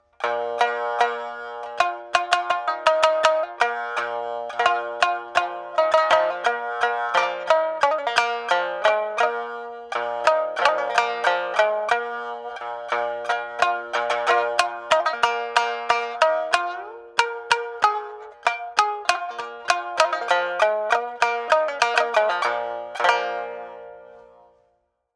Shamisen